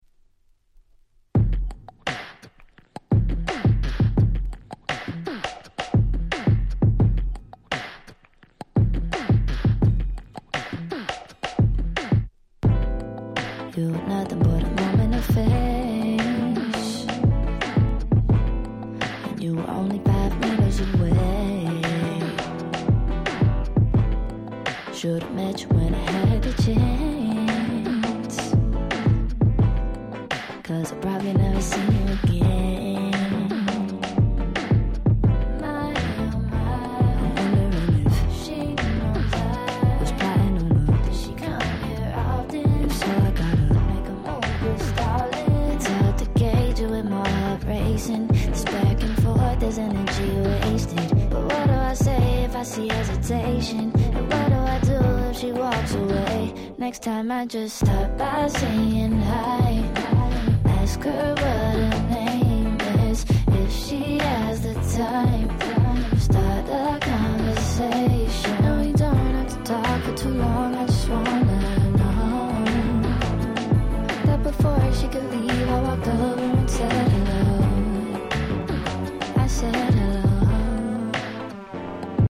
18' Smash Hit R&B/Neo Soul LP !!